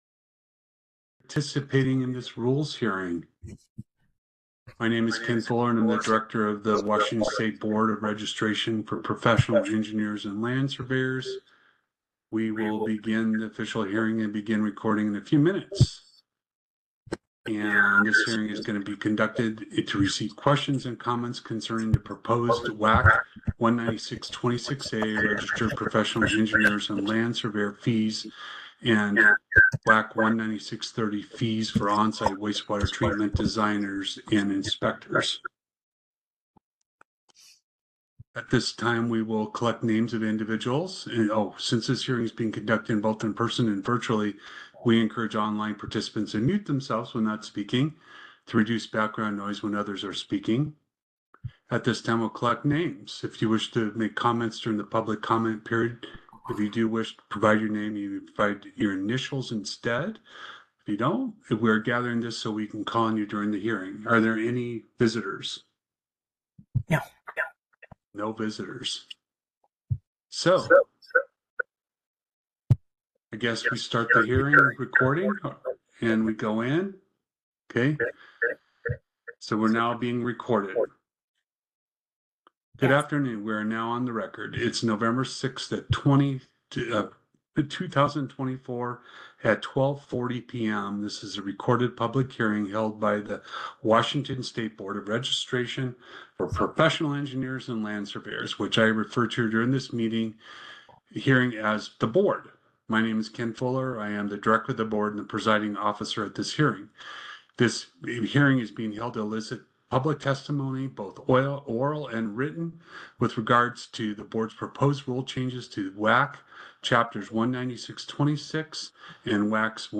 A public hearing was held on January 29, 2025
Public hearing